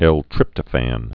(ĕltrĭptə-făn)